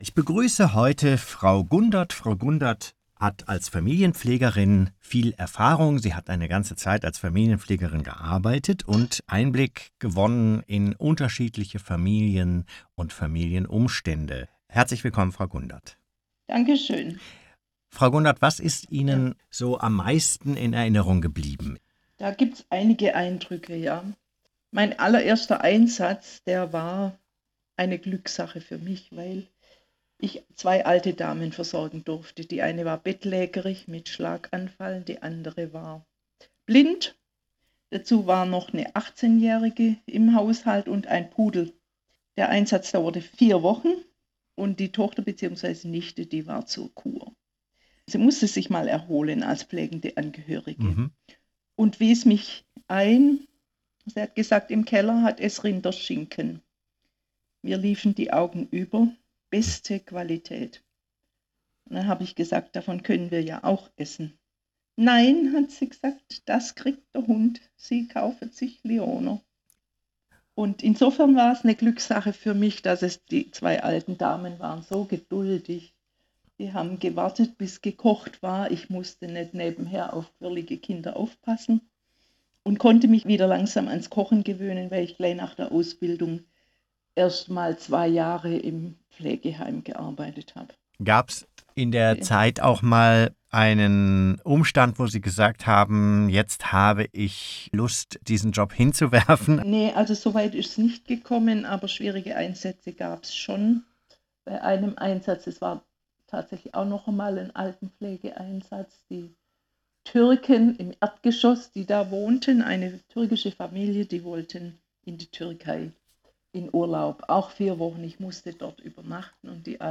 Außerdem finden sich hier Interviews mit ehemaligen Schwestern und heute tätigen Familienpfleger:innen, die von ihren persönlichen Erfahrungen während der Ausbildung und in ihren Einsätzen berichten.